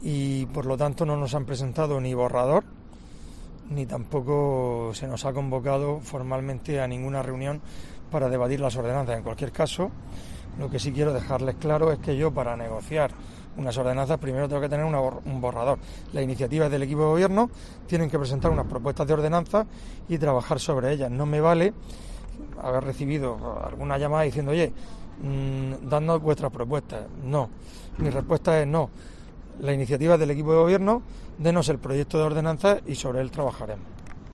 Fulgencio Gil, portavoz del PP sobre ordenanzas fiscales